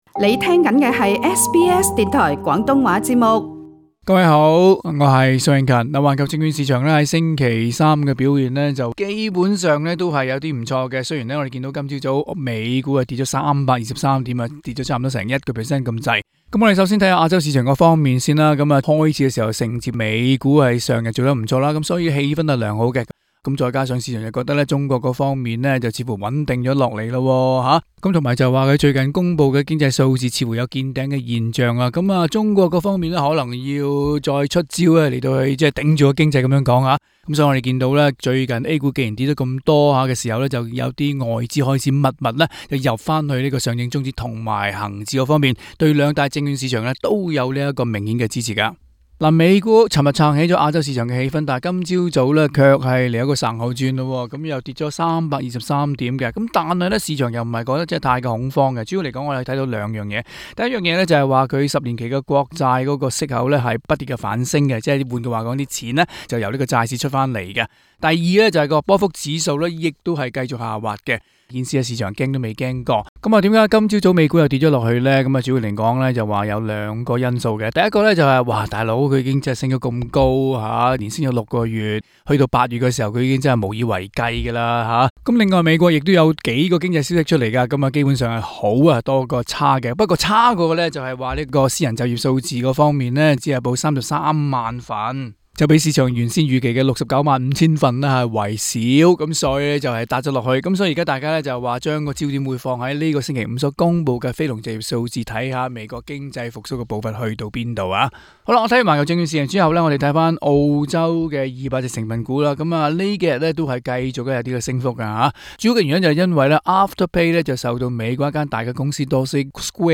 詳情請收聽今日的訪談内容。